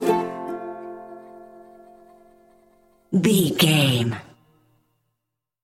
Ionian/Major
acoustic guitar
ukulele
slack key guitar